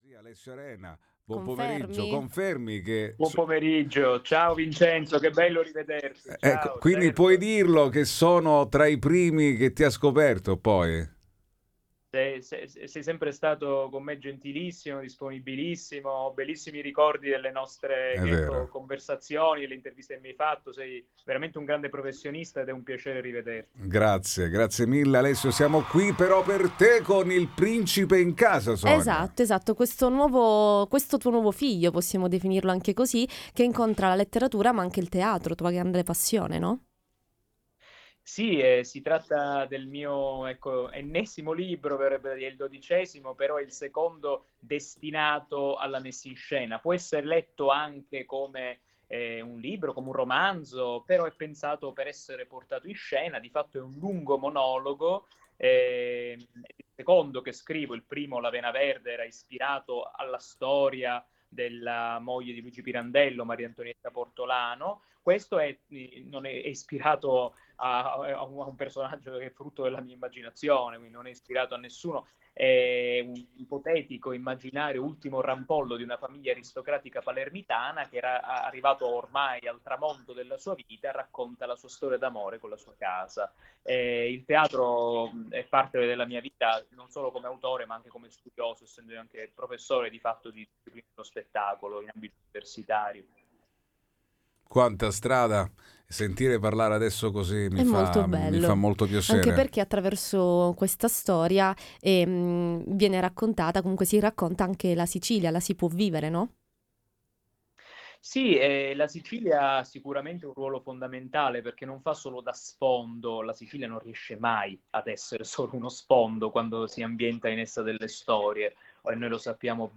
All Inclusive Interviste 14/04/2026 12:00:00 AM